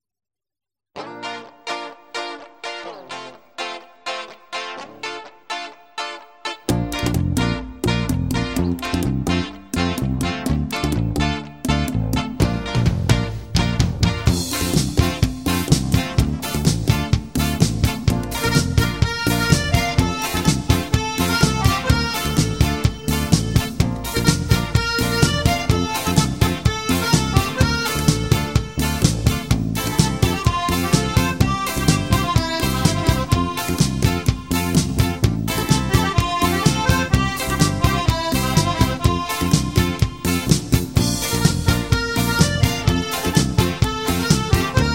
Folk / Celta/ World Music